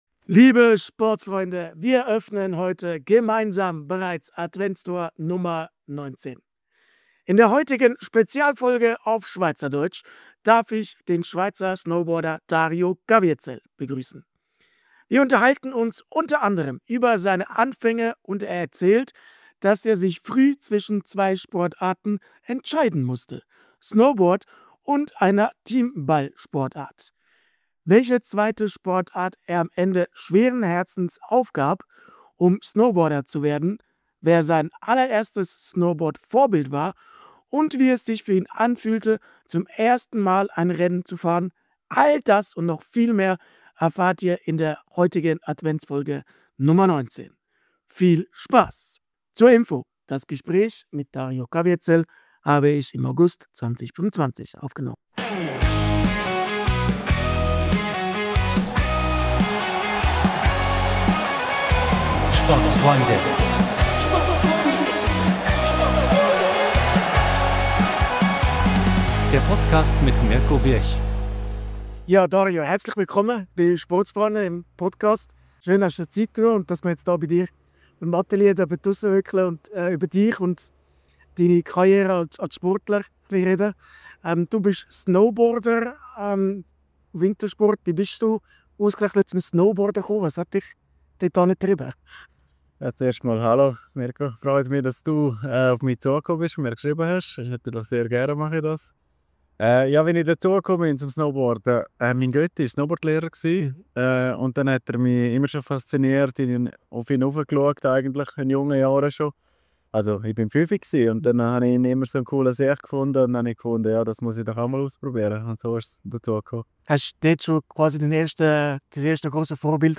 Spezialfolge auf Schweizerdeutsch! ~ Mixed-Sport Podcast